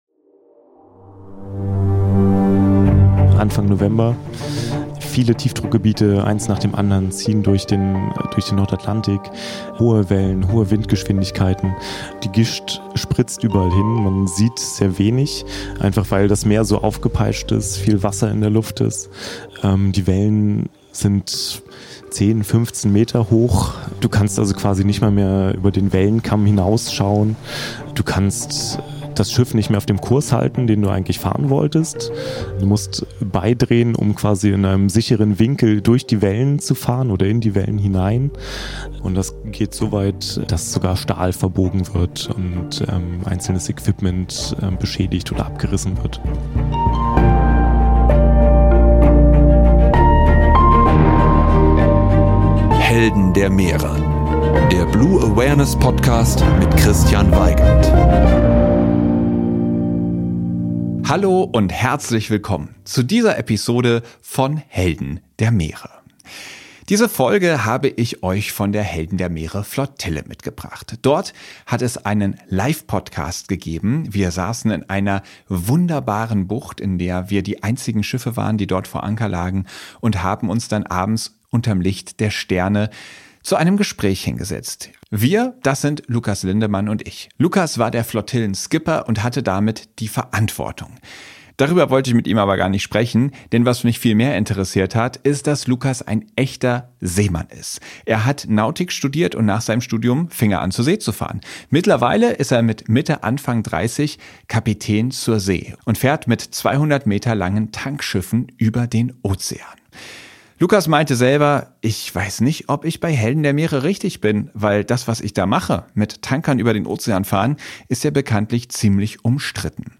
während der Flottille einen Live-Podcast aufgenommen